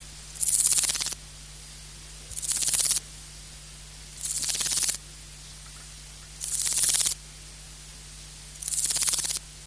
Акустические сигналы саранчового
Акустические сигналы: одиночный самец, Могольская Народная Республика, Убсу-Нурский аймак, окрестности Дзун-Гоби сомона, запись
Температура записи 33-35° С.